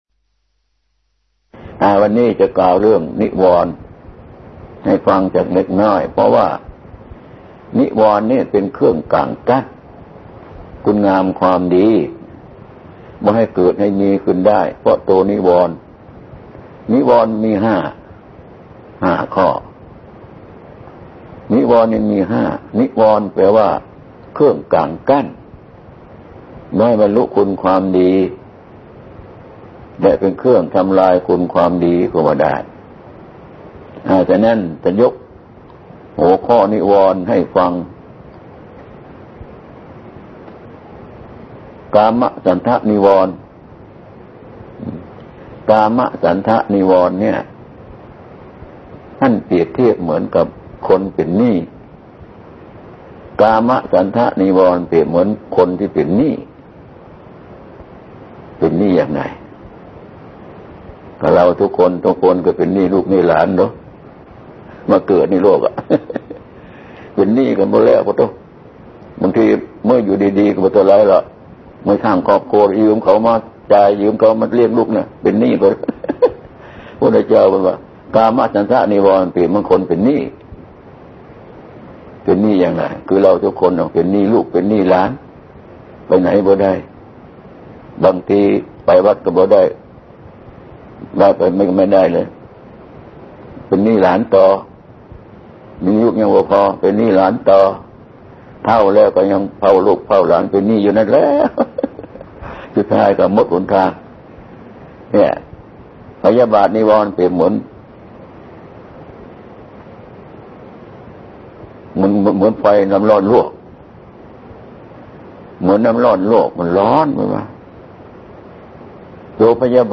เทศน์ ณ. เครื่อฃิเมนต์ไทย